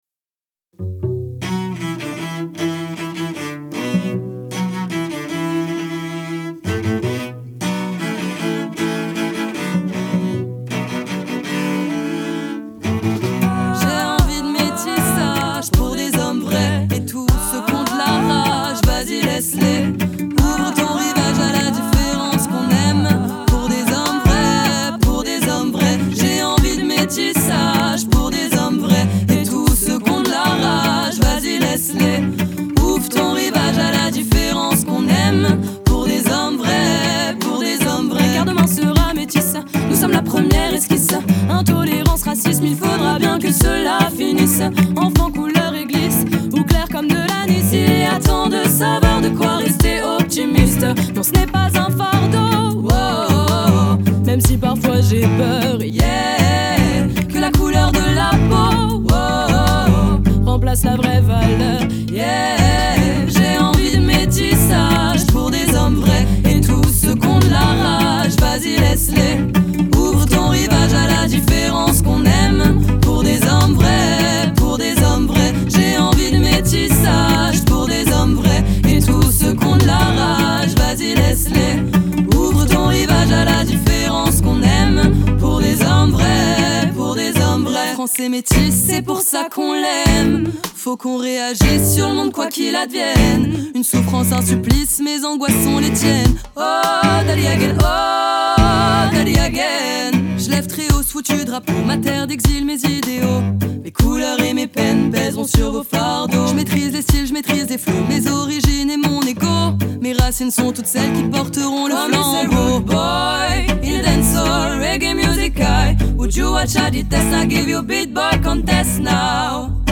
Строго, без излишеств – вокал, виолончель и барабан.
Genre: French Music, Pop